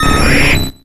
Audio / SE / Cries / PIDGEOTTO.ogg